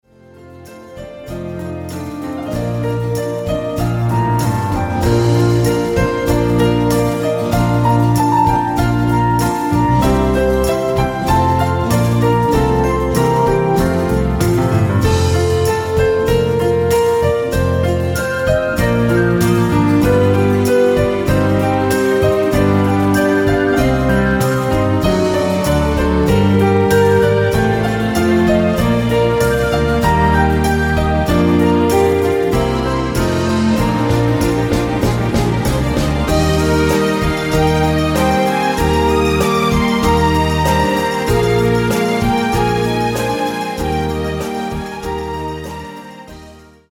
Delighting Light Classic